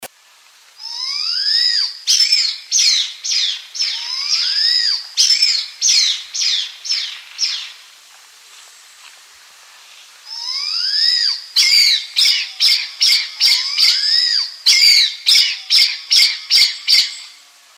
India’s Blood pheasant, named for its splotches of deep red feathers around its eyes and breast, whirs its call, almost as if imitating a firework.
BLOOD-PHEASANT_.mp3